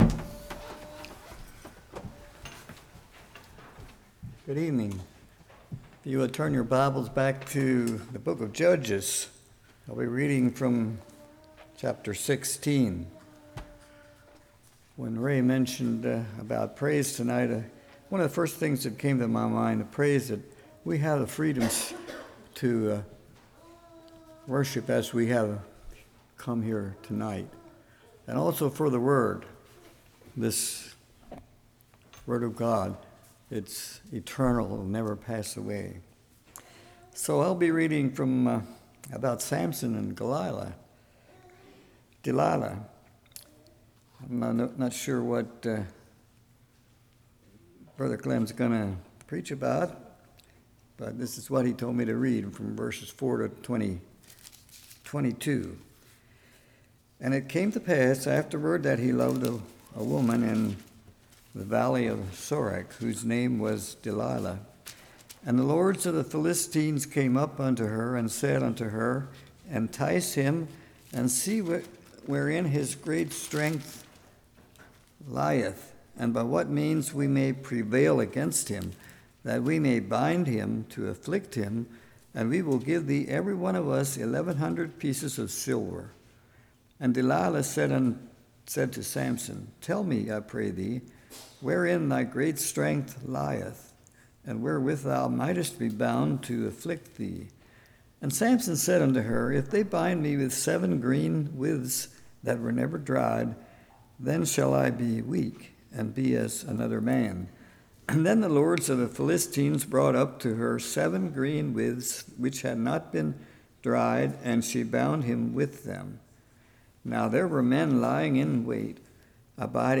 Judges 16:4-22 Service Type: Evening Who is a friend?